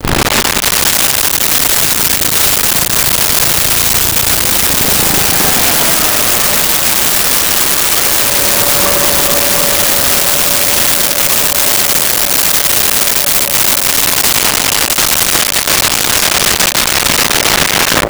Sea Helicopter Fast By
Sea Helicopter Fast By.wav